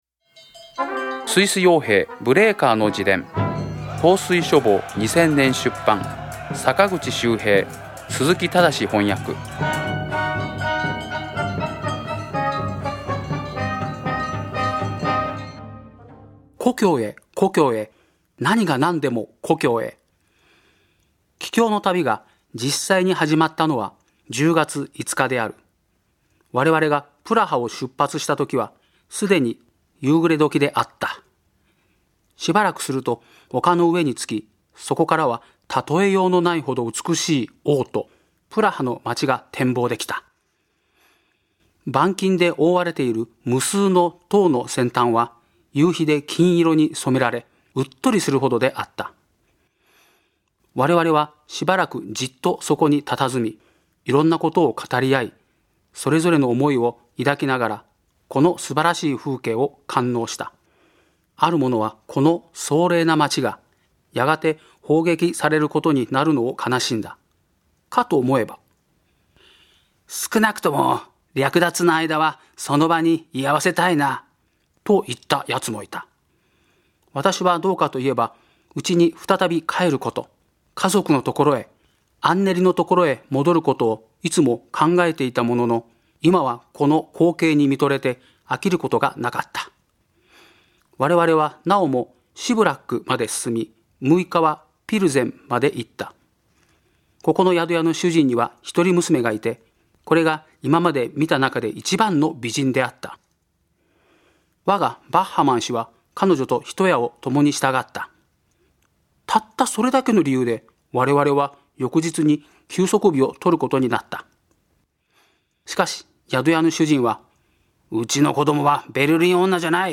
朗読『スイス傭兵ブレーカーの自伝』第62回